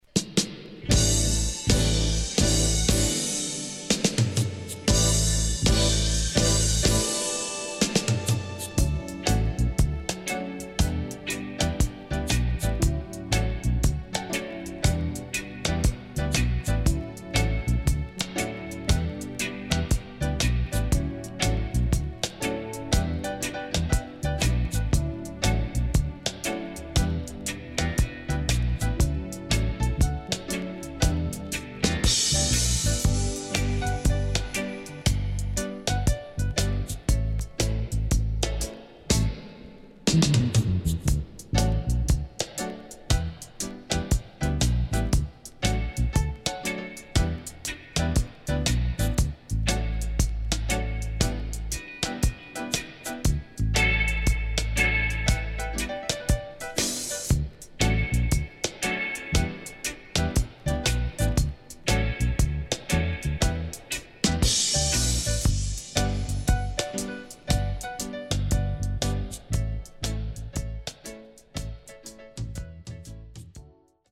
UK Lovers Classic & Dubwise.Good Condition